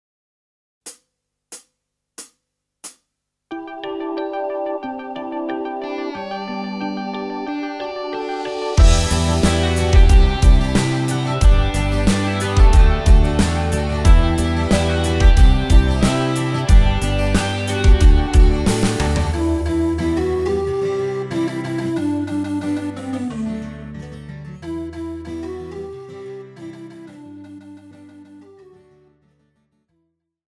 Žánr: Pop
BPM: 91
Key: Em